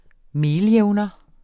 Udtale [ -ˌjεwnʌ ]